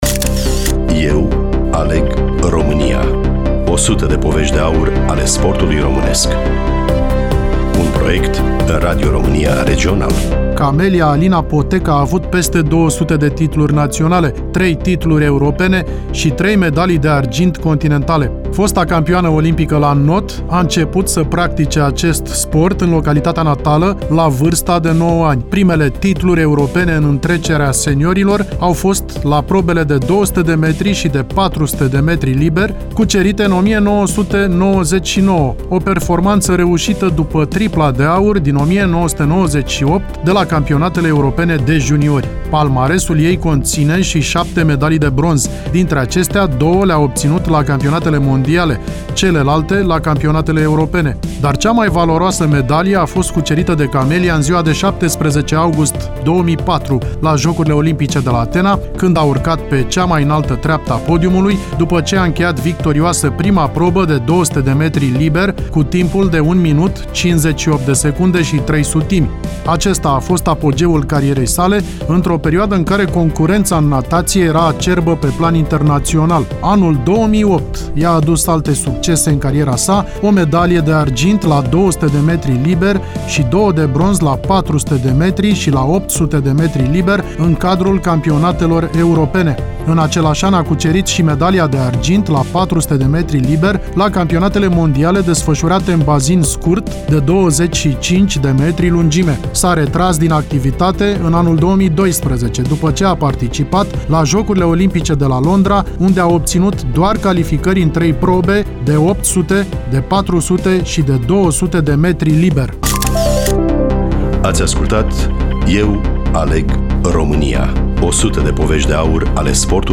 Studioul Radio România Constanta